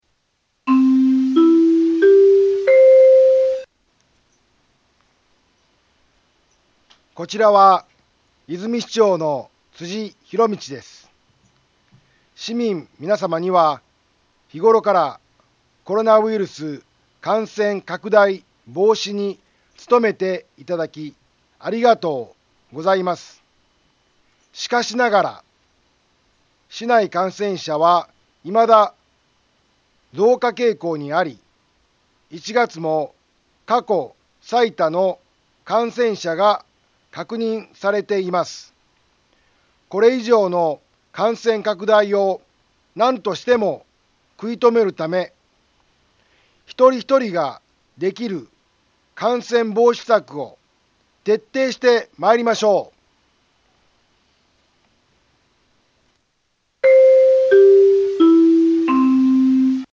Back Home 災害情報 音声放送 再生 災害情報 カテゴリ：通常放送 住所：大阪府和泉市府中町２丁目７−５ インフォメーション：こちらは、和泉市長の辻ひろみちです。